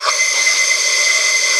Index of /90_sSampleCDs/Spectrasonics - Bizarre Guitar/Partition F/10 FEEDBACK